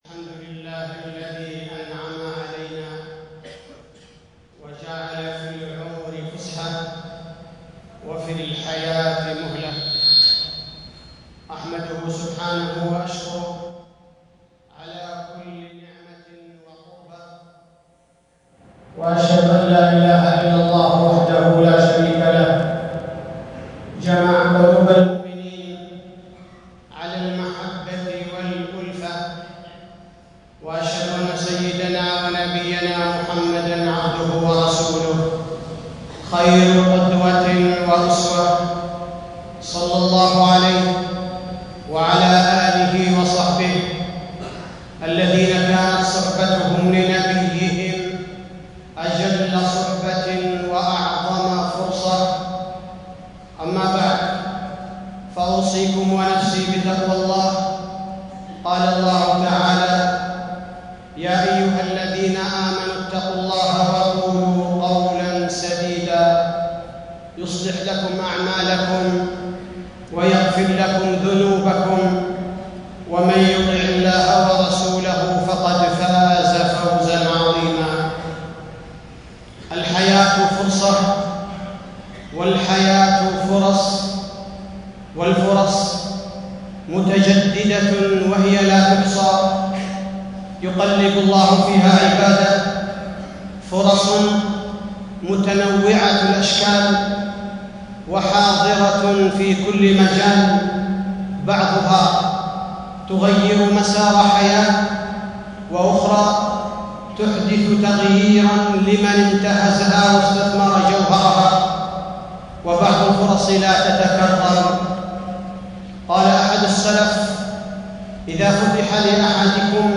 تاريخ النشر ٢٨ محرم ١٤٣٦ هـ المكان: المسجد النبوي الشيخ: فضيلة الشيخ عبدالباري الثبيتي فضيلة الشيخ عبدالباري الثبيتي اغتنام الفرص قبل فوات الأوان